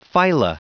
Prononciation du mot phyla en anglais (fichier audio)
Prononciation du mot : phyla